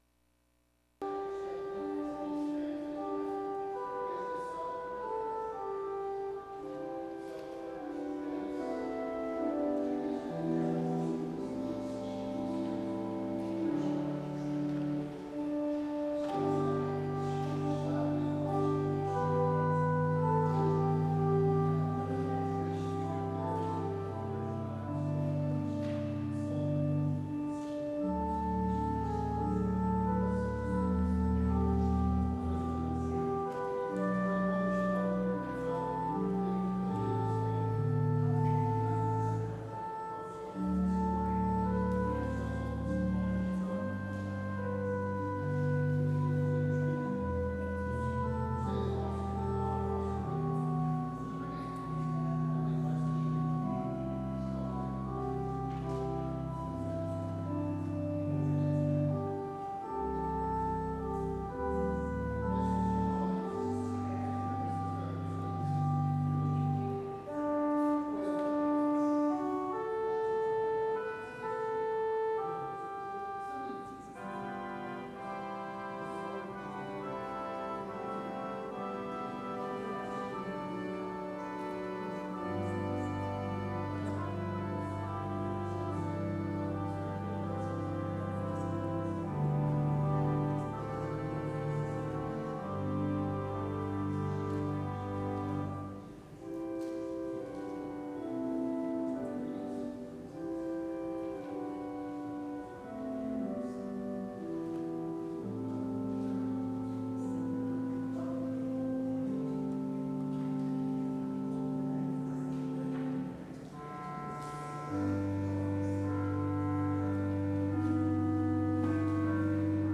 Worship Service Sunday Sept 14, 2025; Holy Cross Day